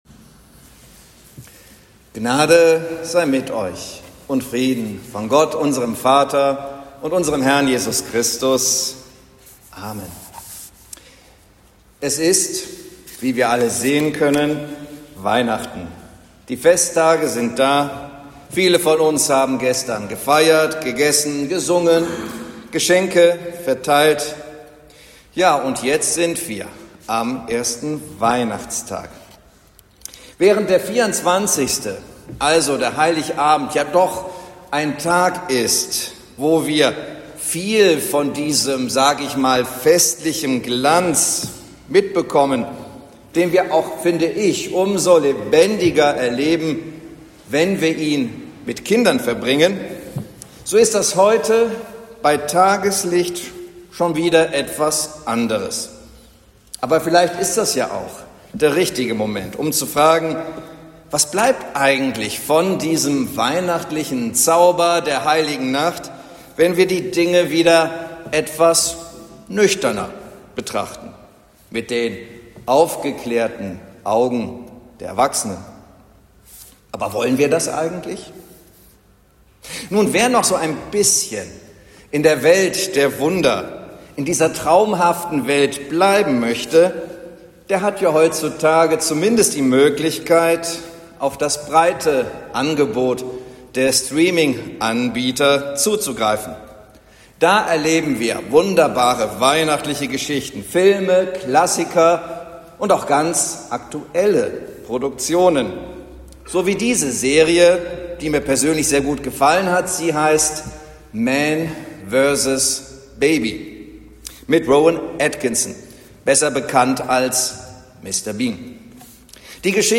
Predigt zum 1. Weihnachtsfeiertag